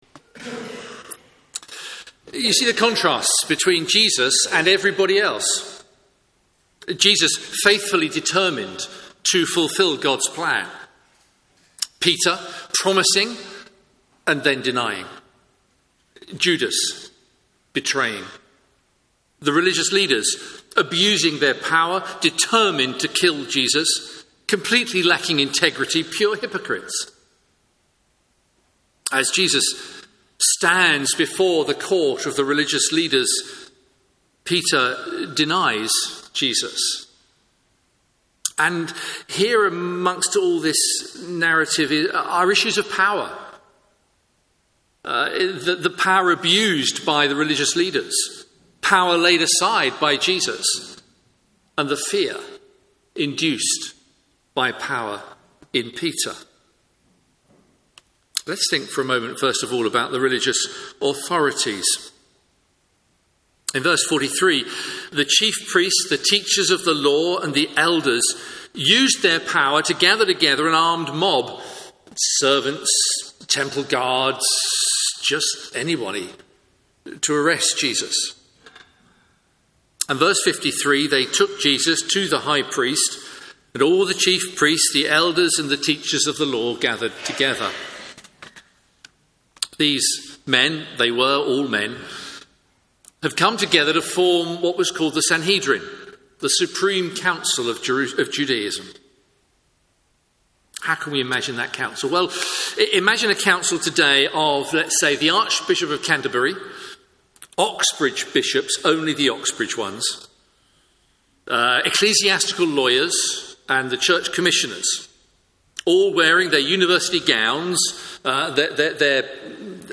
Media for Morning Service on Sun 08th Oct 2023 10:30 Speaker
Theme: Sermon In the search box please enter the sermon you are looking for.